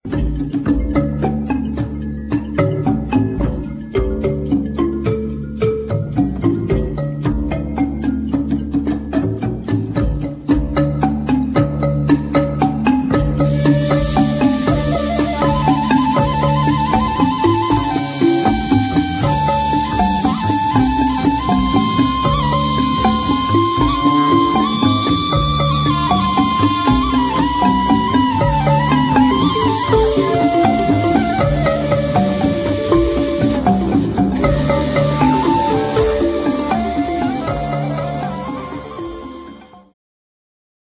flety, lira korbowa, drumla, tarogato, śpiew gardłowy, śpiew
congas (4), śpiew (4)